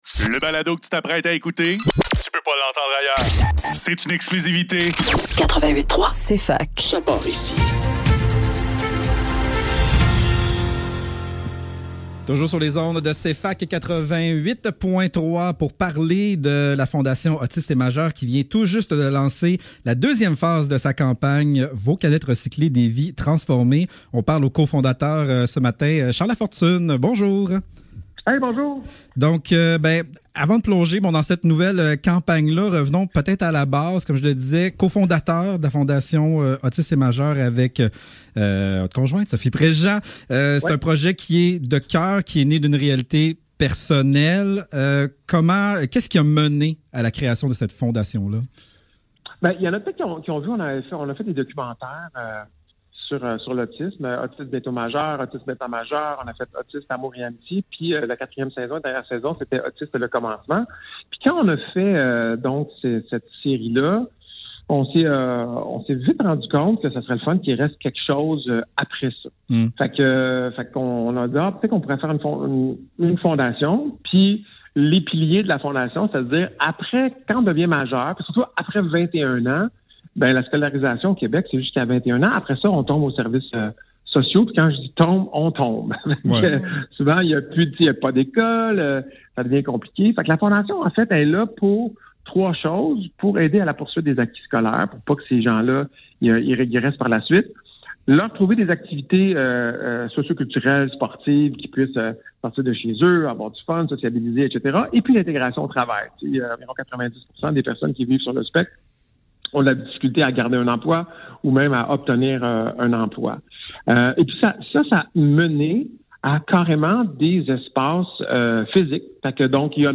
Le Neuf - Entrevue : Charles Lafortune - 08 Avril